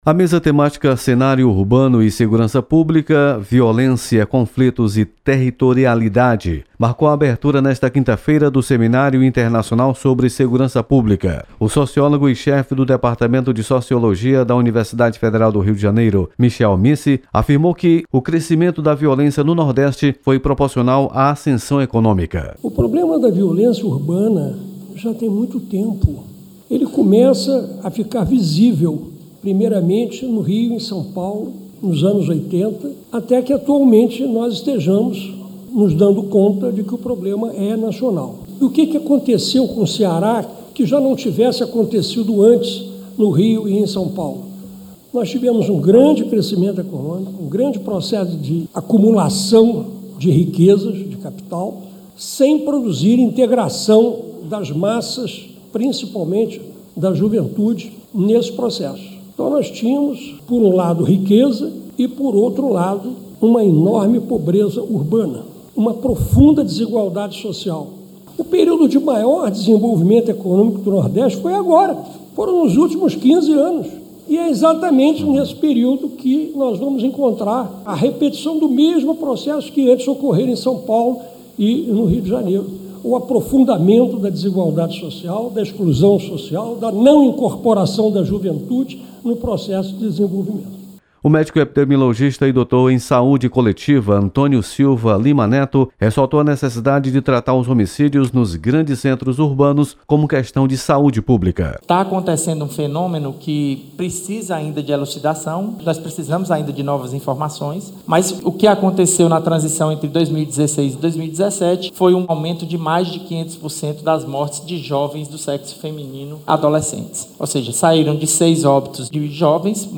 Você está aqui: Início Comunicação Rádio FM Assembleia Notícias Seminário